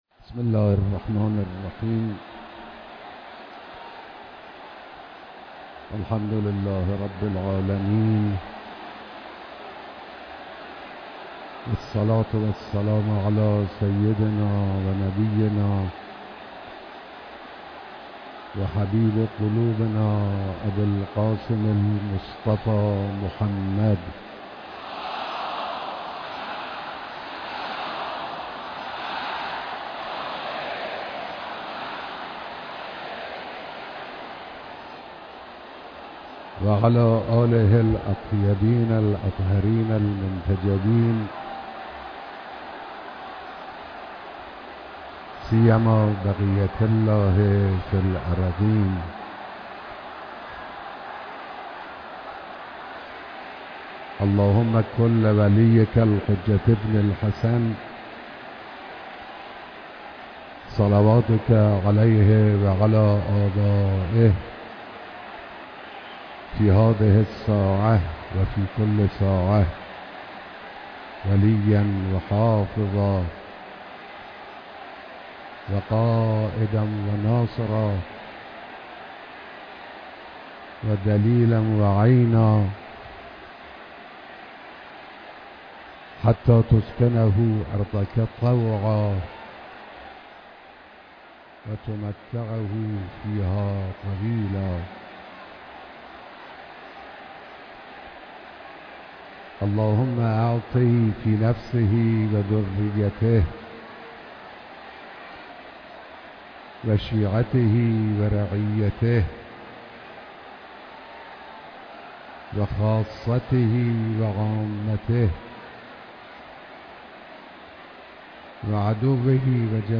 بیانات در مراسم بیست و ششمین سالروز رحلت حضرت امام خمینی رحمه‌الله